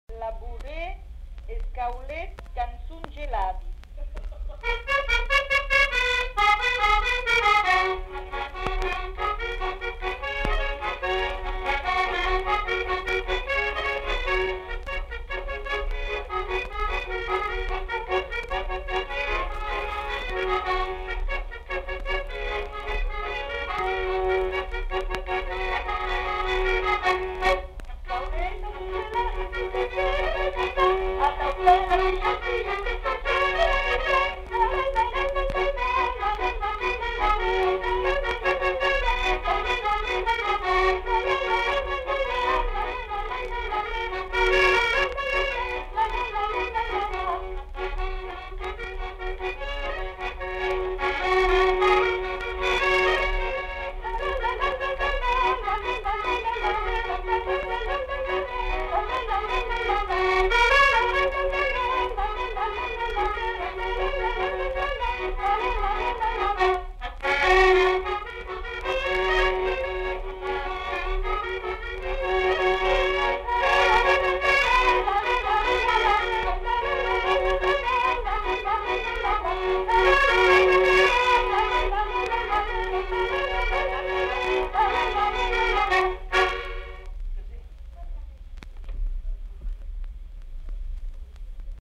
Genre : chanson-musique
Effectif : 1
Type de voix : voix de femme
Production du son : chanté
Instrument de musique : accordéon chromatique
Danse : bourrée d'Ariège